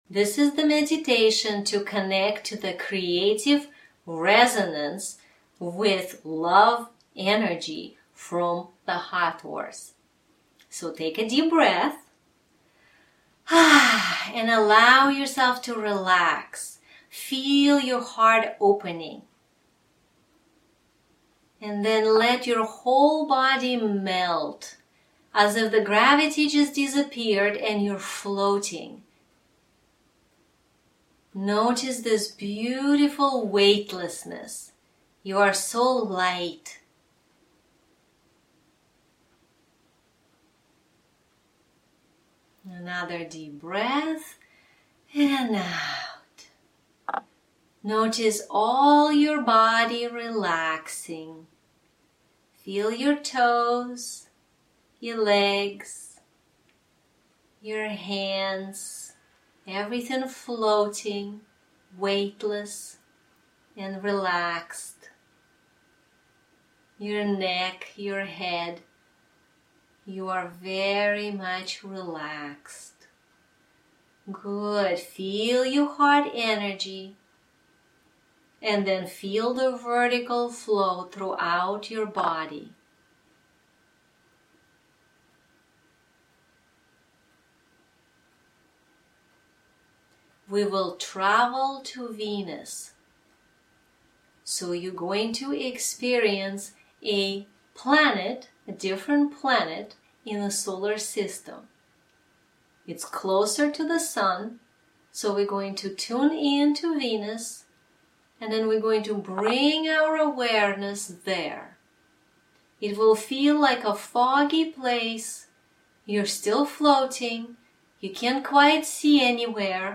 Creative Resonance_Hathors_Meditation.mp3